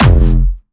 Jumpstyle Kick 9